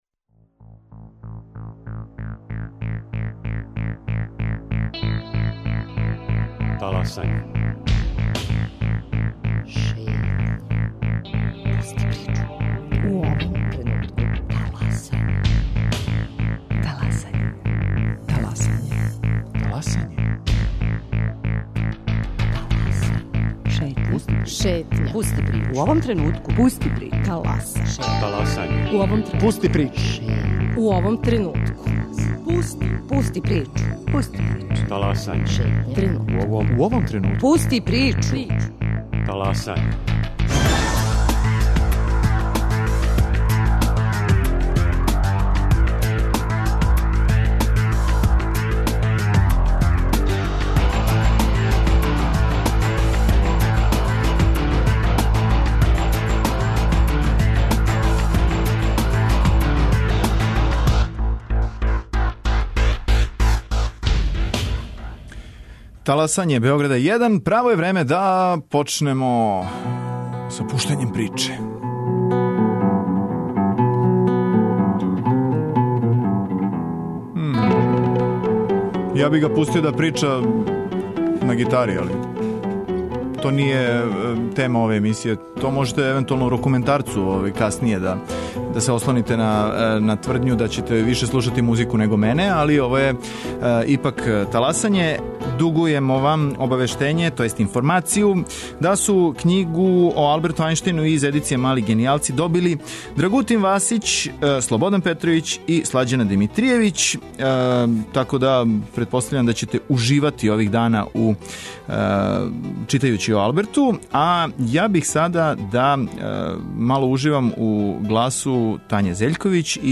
На Велики петак, уживо на Златибору, у Музеју Старо село у Сирогојну, и у студију Радио Београда, учимо како се шарају и осликавају ускршња јаја. Наши саговорници су деца – учесници радионице бојења јаја, као и кустоси Музеја на отвореном, који ће говорити о традиционалним ускршњим обичајима и подсетити нас којих се обичаја ваља придржавати.